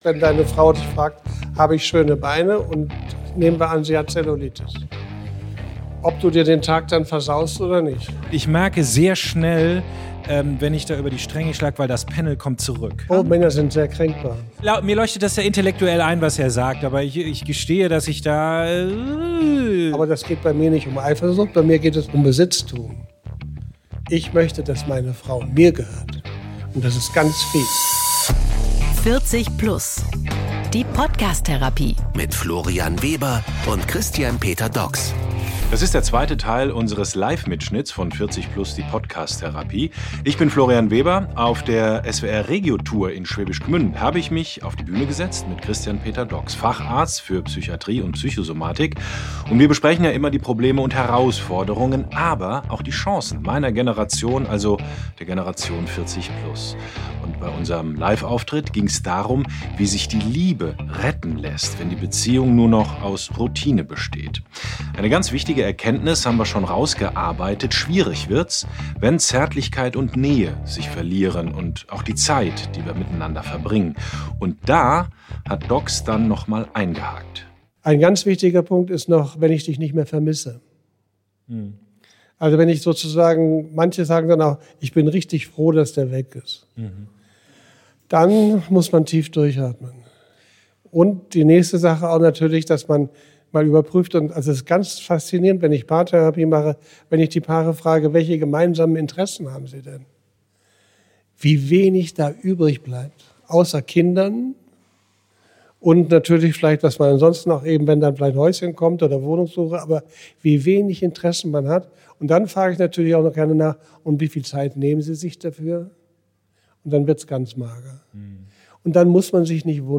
Beschreibung vor 4 Monaten Wenn Paare es schaffen ehrlich zu kommunizieren, haben sie eine gute Chance ihre eingeschlafene Beziehung zu retten. In der neuen Folge von „40+ die Podcast Therapie“ sprechen Florian Weber und Psychiater Christian Peter Dogs genau darüber: Was passiert, wenn außer den Kindern keine gemeinsamen Interessen mehr da sind und darüber wenn Nähe, Kommunikation und Zärtlichkeit zur Ausnahme werden. Viel Spaß mit der neuen Folge "40+ Die Podcast-Therapie", live aus Schwäbisch Gmünd bei der SWR Regio Tour.